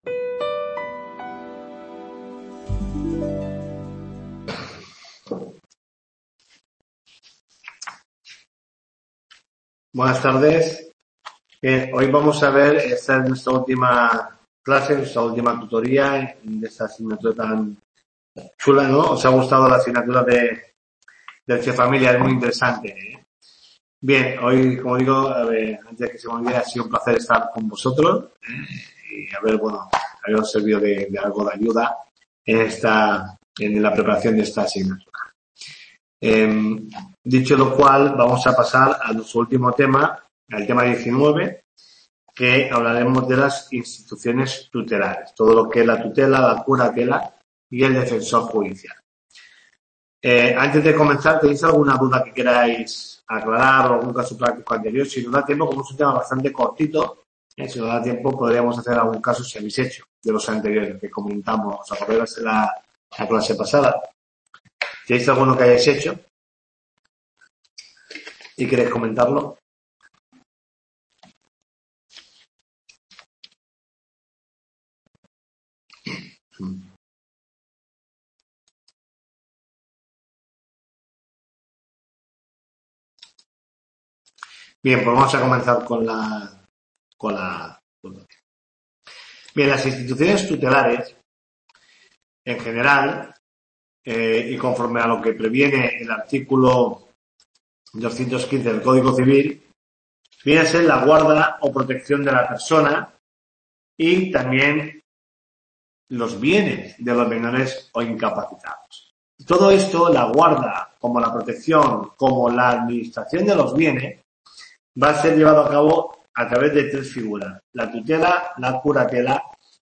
TUTORIA 12